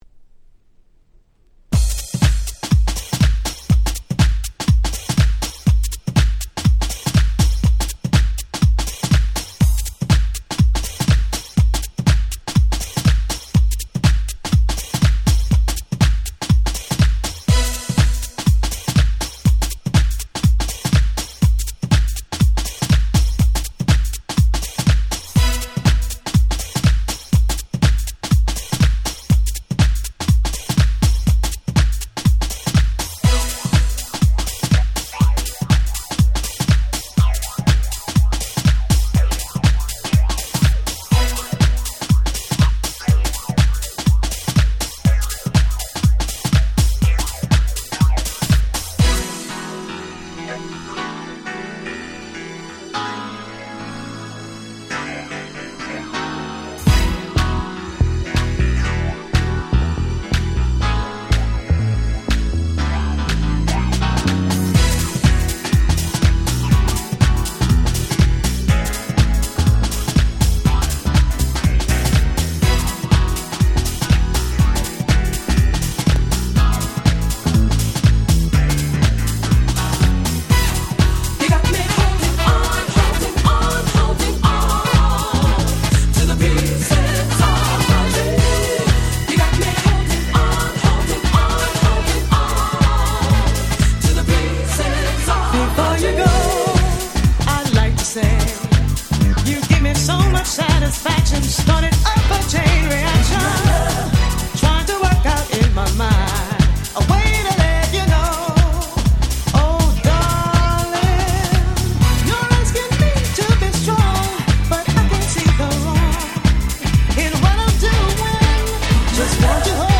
94' Very Nice Acid Jazz / Vocal House !!